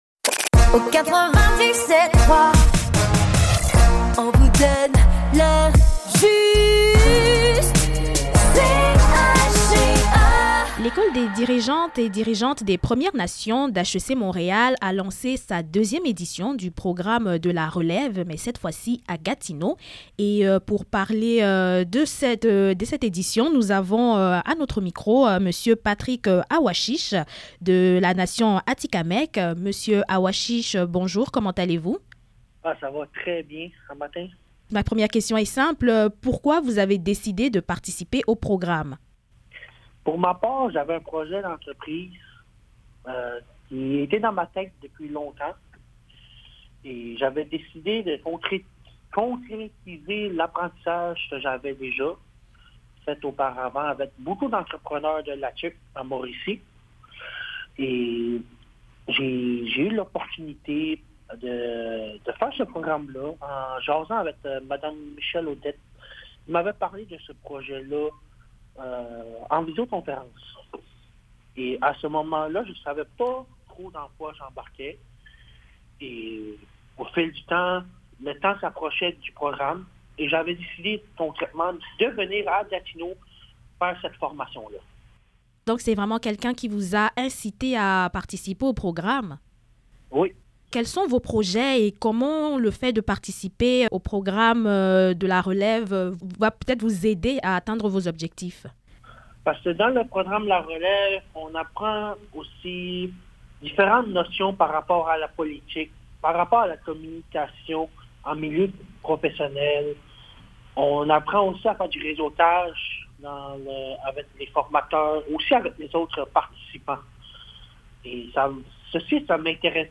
Entrevues
Retrouvez les différentes entrevues réalisées par les membres du service de l'information de CHGA.